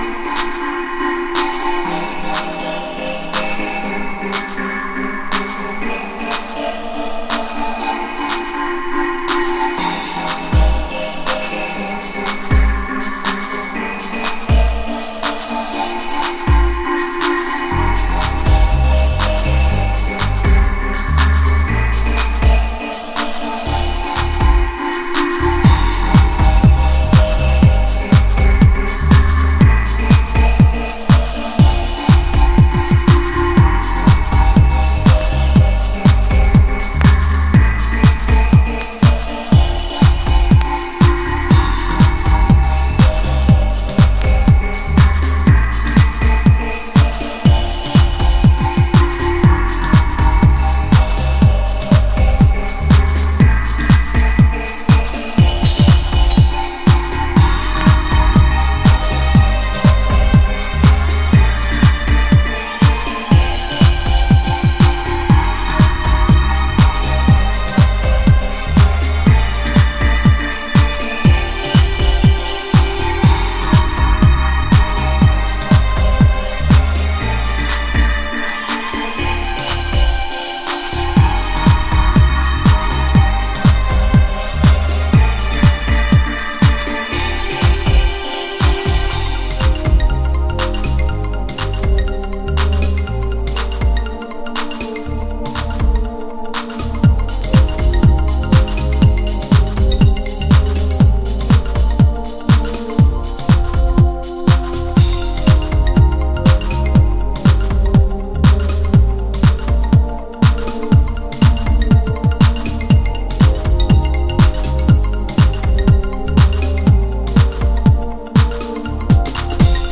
なんとも柔らかいビートメークとシルキーなシンセワーク、情景的なディープハウス。